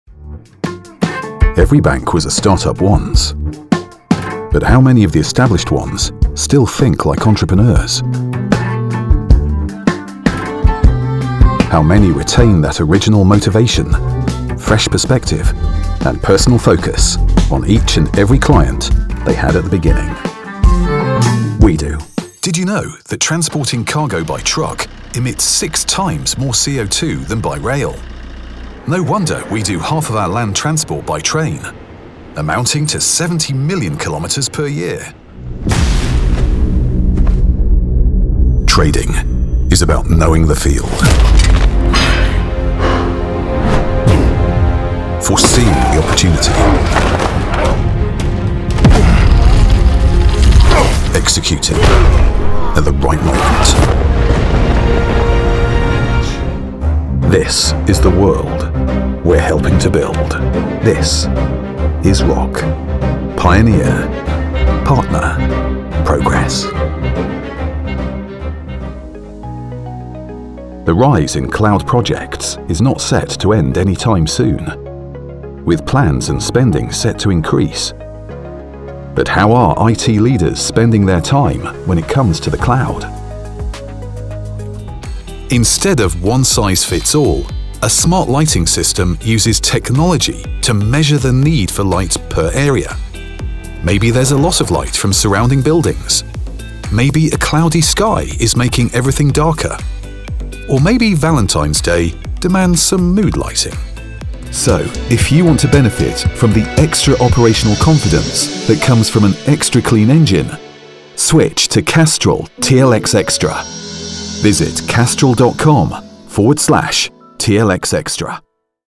Male Voice Over Artists Actors & Talent for Hire Online
Warm calm friendly ear massaging voice for narrations, dynamic funny affirrmative active for commercials, smooth professional corporate for presentations and flexible chameleon for characters....